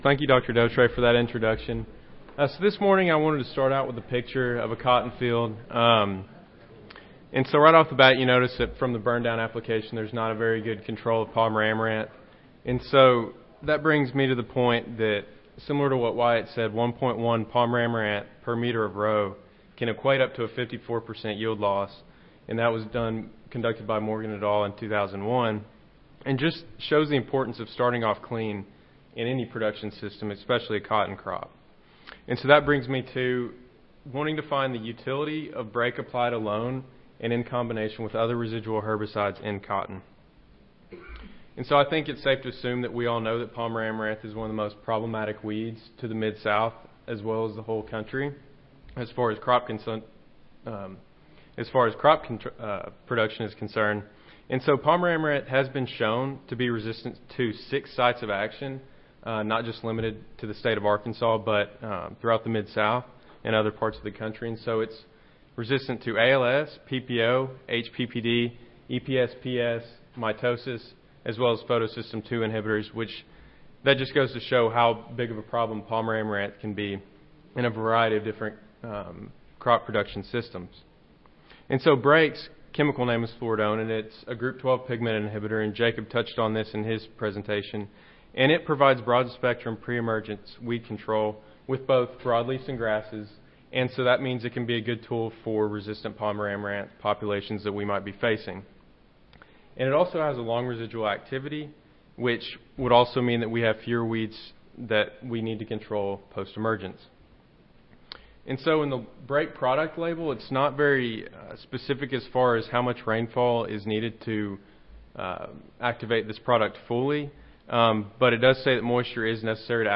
Recorded Presentation Evaluating weed control of Brake applied alone and in combination with other residual herbicides in cotton.